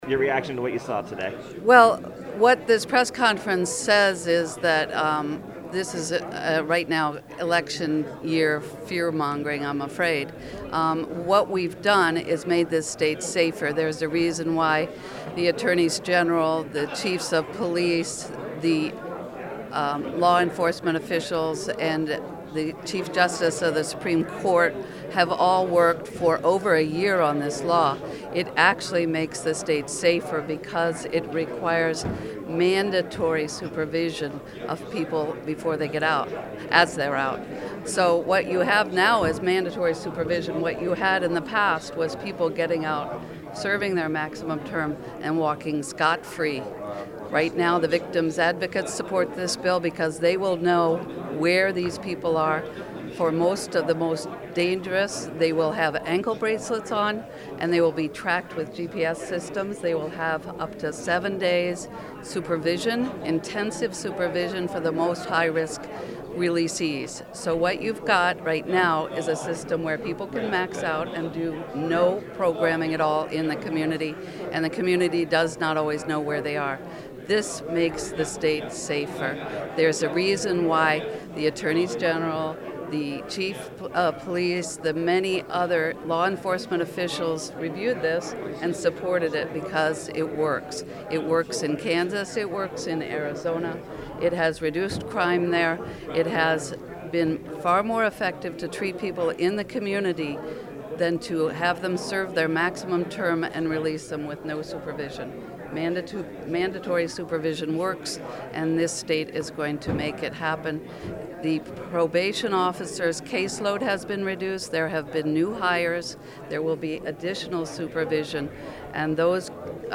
Interview- Sylvia Larsen
Interview-SylviaLarsen.mp3